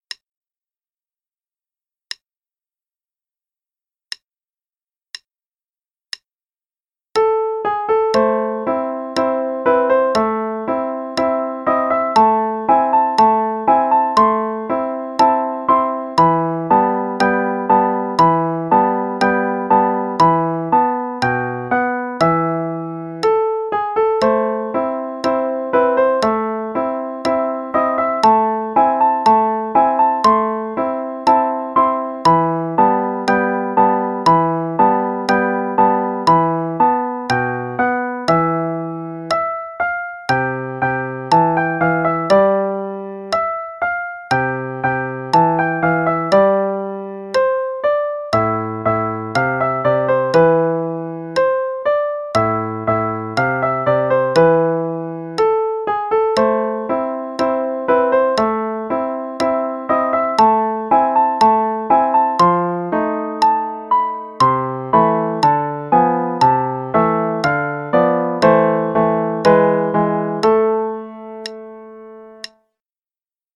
Rondo alla Turca (both hands, qn=60)
Play-along_Mozart - Rondo alla Turca (qn=60).mp3